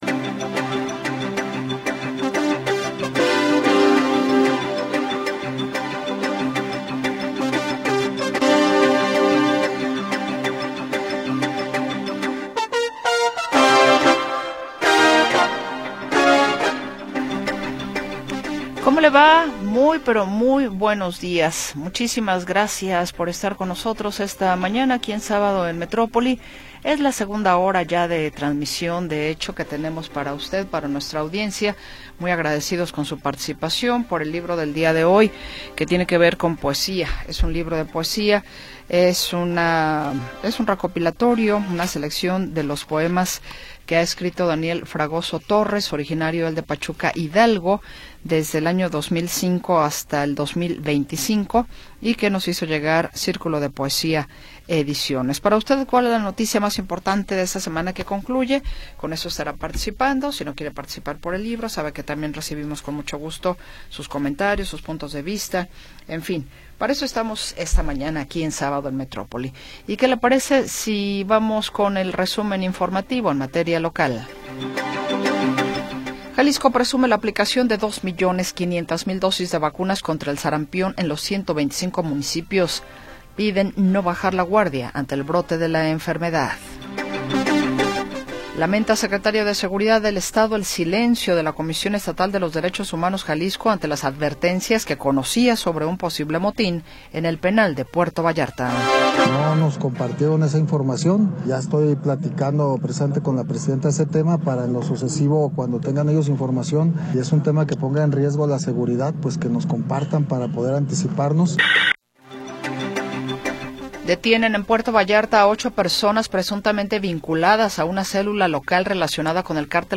28 de Febrero de 2026 audio Noticias y entrevistas sobre sucesos del momento